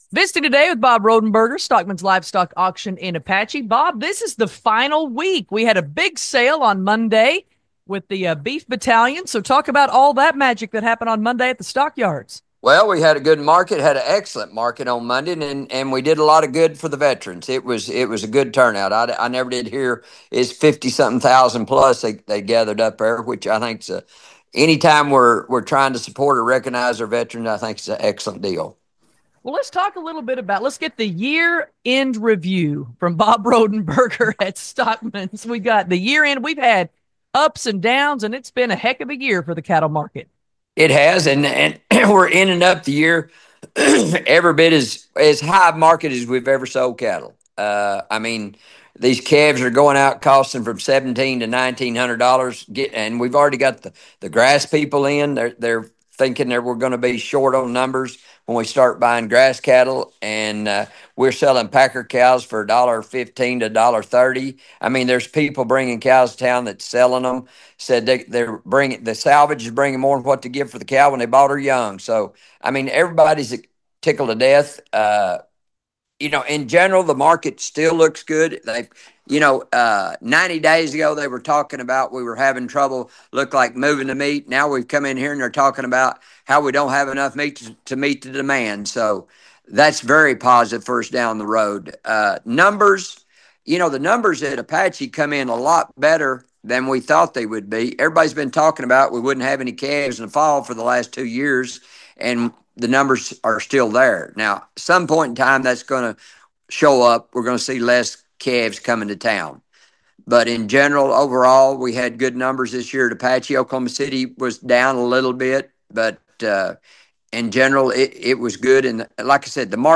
Here's our regular feature that is a part of the Monday Daily Email- market commentary